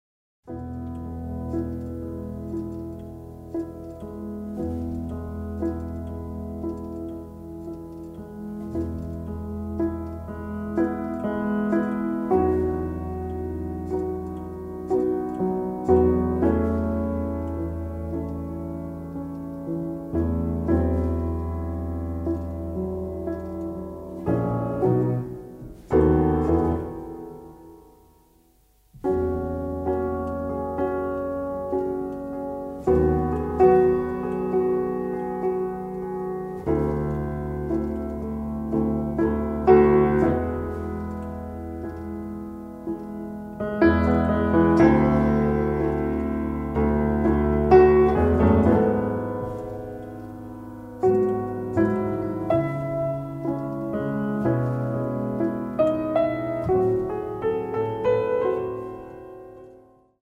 Каталог -> Джаз и около -> Тихие эмоции
это музыка, сыгранная на рояле.